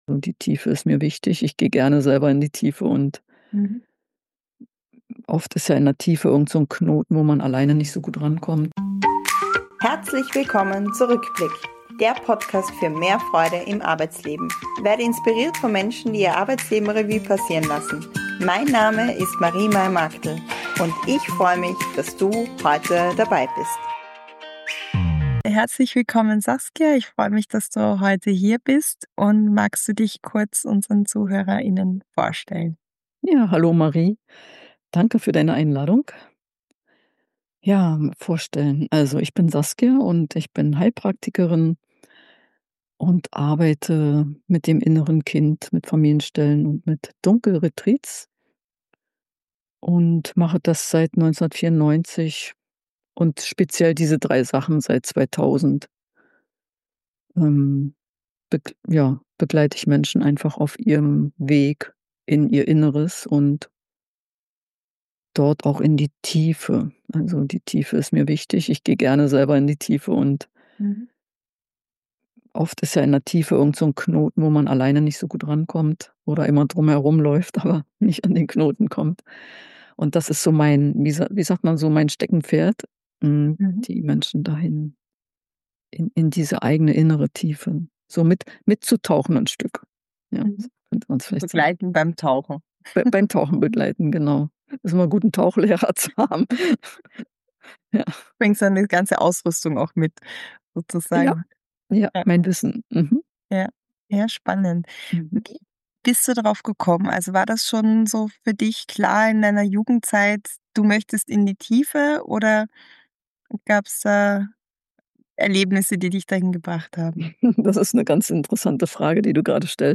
Wie Angst zur Verbündeten wird Warum Heilung immer bei uns selbst beginnt Was emotionale Tiefen mit Lebensmut zu tun haben Und wie wir Frieden IN uns schaffen können, gerade wenn die Welt laut ist Ein Gespräch über Lebensgestaltung, innere Kraftquellen und darüber, wie wichtig es ist, bei sich selbst „zuhause“ zu sein.